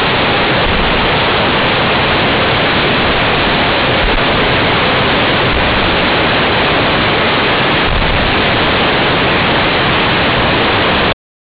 waterfall of SHIRAITONO sound of waterfall
fall.wav